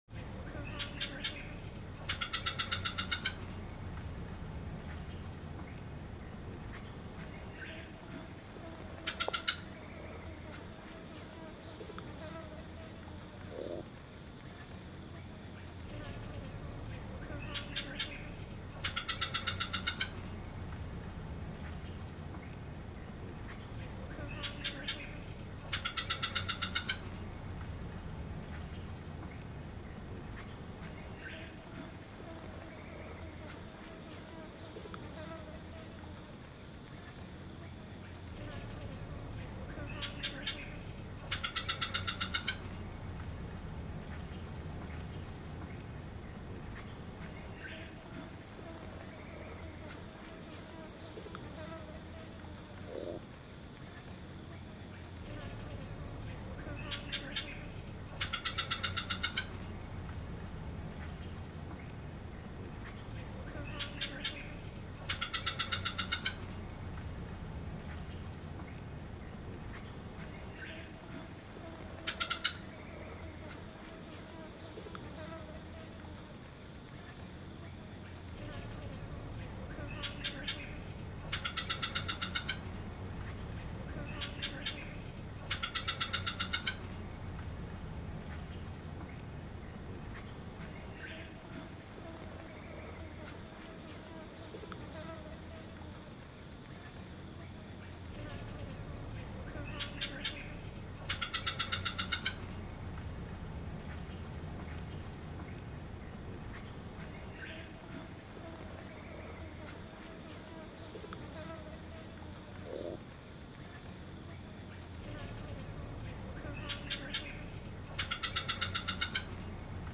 دانلود آهنگ پرنده 19 از افکت صوتی انسان و موجودات زنده
جلوه های صوتی
دانلود صدای پرنده 19 از ساعد نیوز با لینک مستقیم و کیفیت بالا